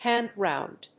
アクセントhánd róund